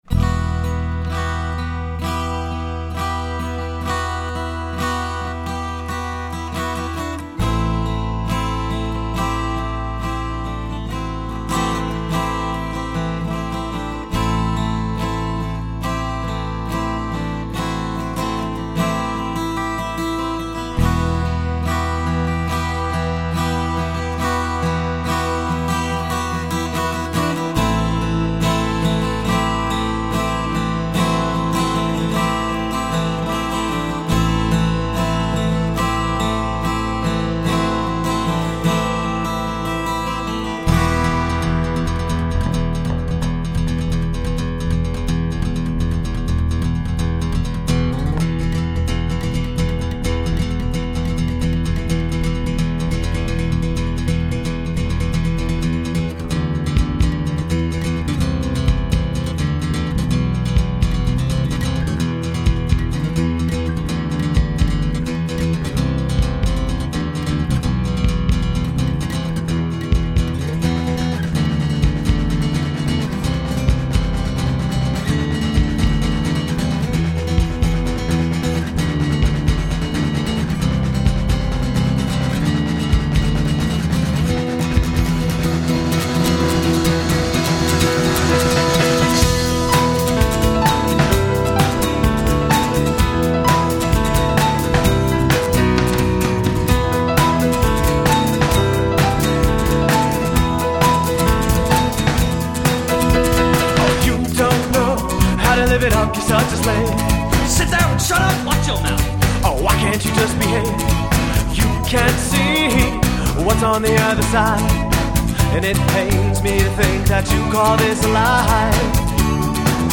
Guitar, Vocals
Drums
Piano, Organ
Bass Guitar